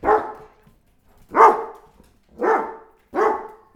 dog-dataset
dogs_0012.wav